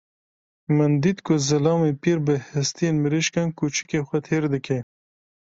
Pronounced as (IPA) /piːɾ/